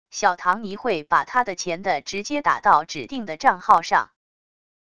小唐尼会把他的钱的直接打到指定的账号上wav音频生成系统WAV Audio Player